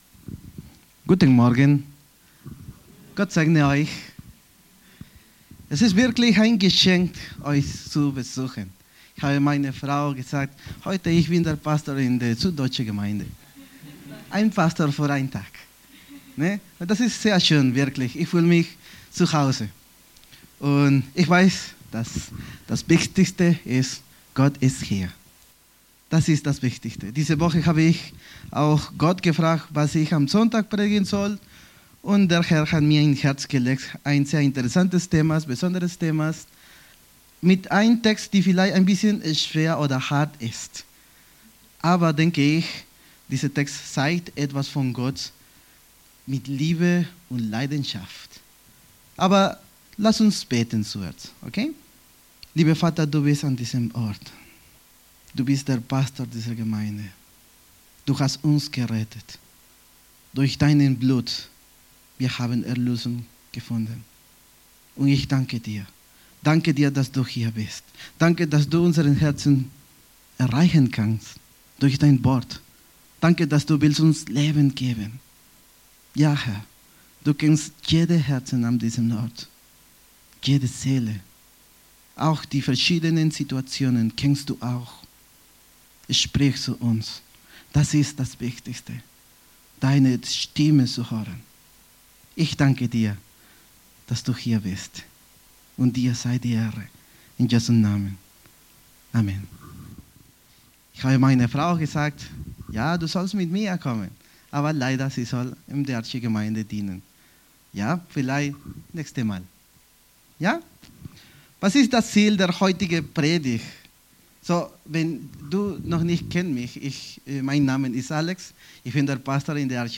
Predigt vom 16. Februar 2025 – Süddeutsche Gemeinschaft Künzelsau